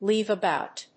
アクセントléave abóut [aróund]